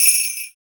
13 J.BELLS.wav